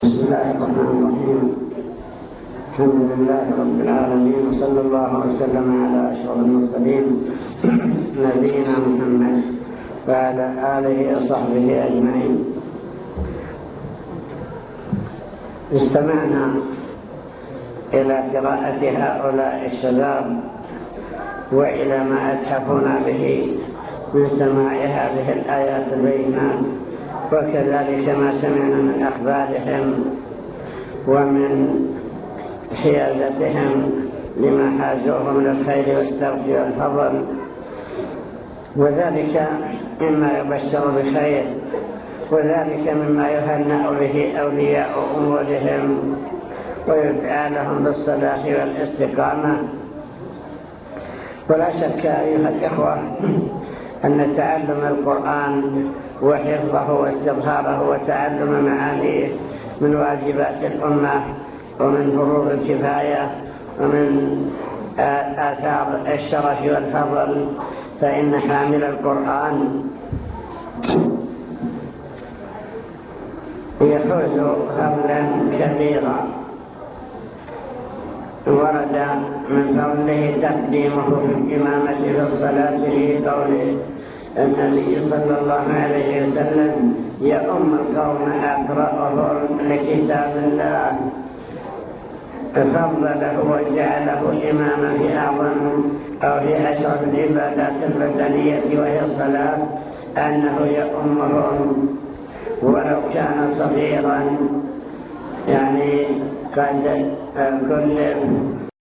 المكتبة الصوتية  تسجيلات - لقاءات  احتفال التحفيظ مع كلمة الشيخ فضل القرآن